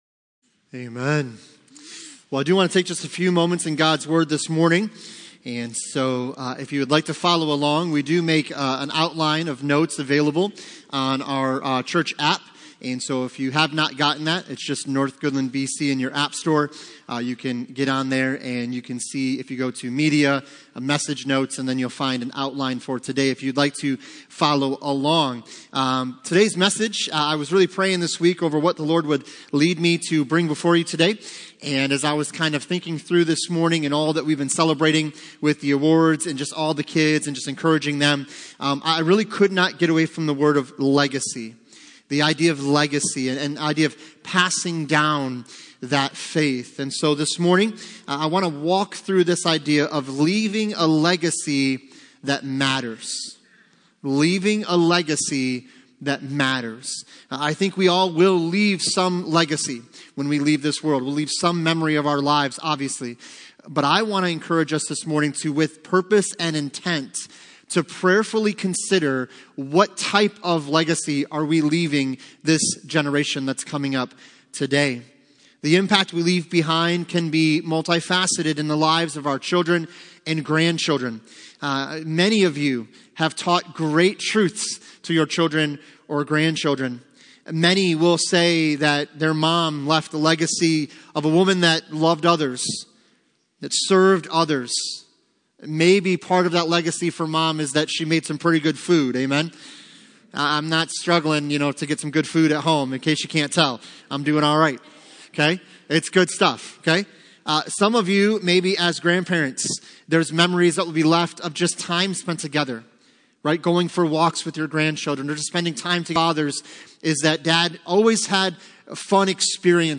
Passage: 2 Timothy 3:14-17 Service Type: Sunday Morning CCLI Streaming Plus License